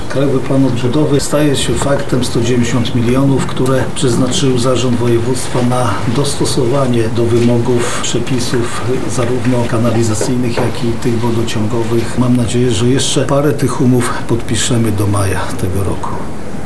– mówił Jarosław Stawiarski, Marszałek Województwa Lubelskiego.